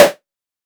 Modular Snare 01.wav